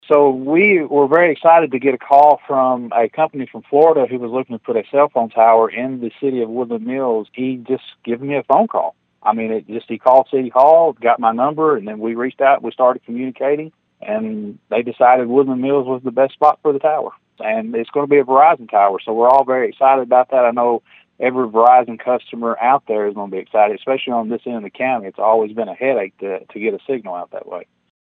Woodland Mills Mayor Joe Lewis told Thunderbolt News about the new cell site in his city.(AUDIO)